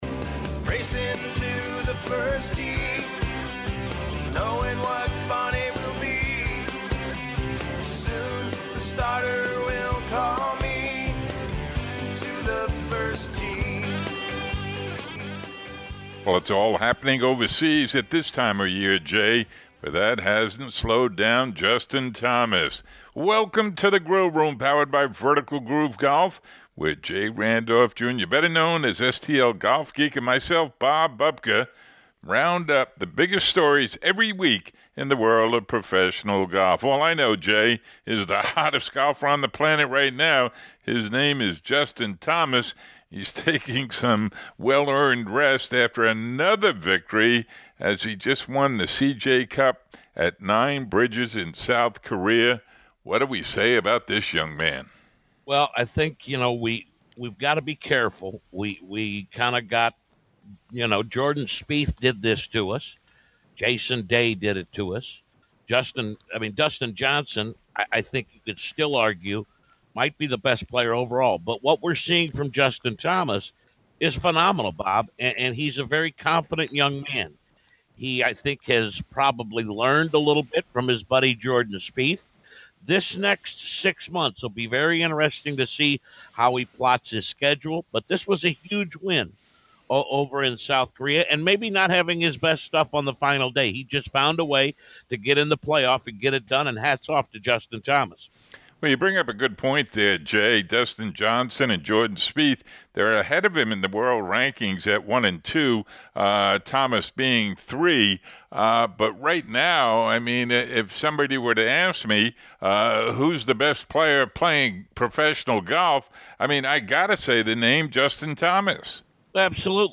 Shot Clock Masters to happen in 2018 in Europe. Preview of HSBC Champions followed by Feature Interview